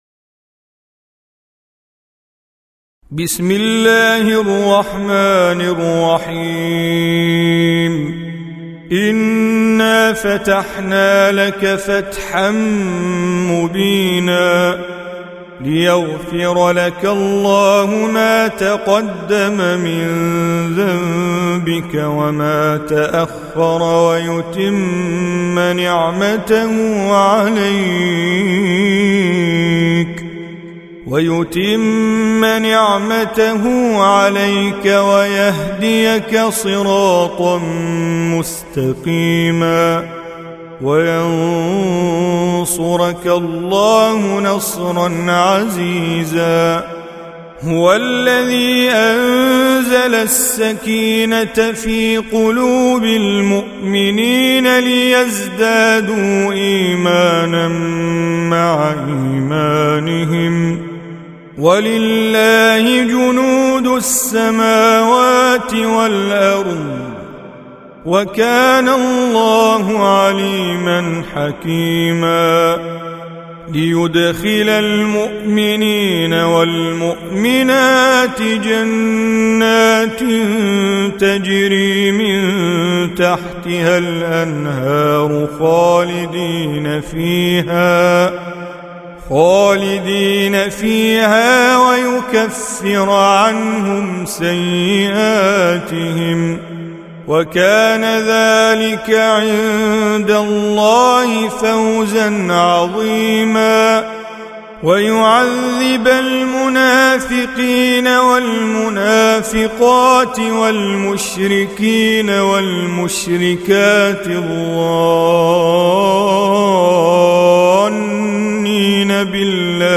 Audio Quran Tajweed Recitation
Surah Repeating تكرار السورة Download Surah حمّل السورة Reciting Mujawwadah Audio for 48. Surah Al-Fath سورة الفتح N.B *Surah Includes Al-Basmalah Reciters Sequents تتابع التلاوات Reciters Repeats تكرار التلاوات